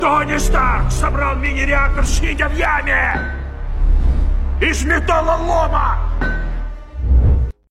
toni stark sobral mini reaktor Meme Sound Effect